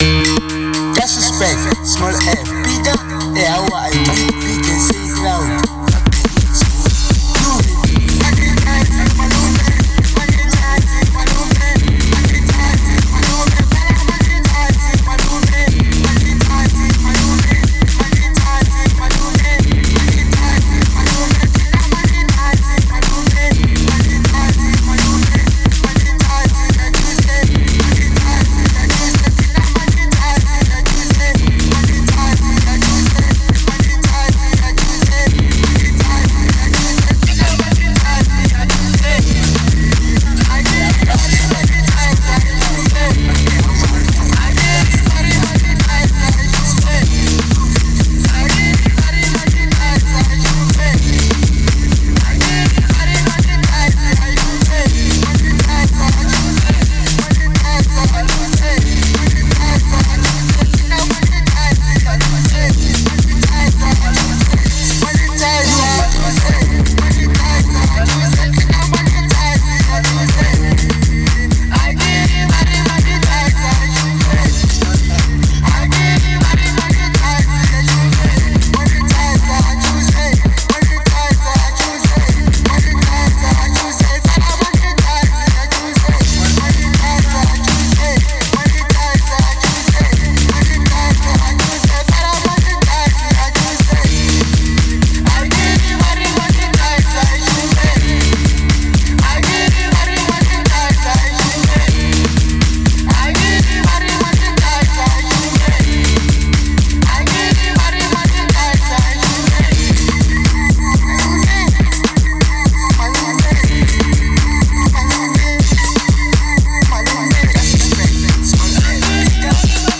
03:54 Genre : Hip Hop Size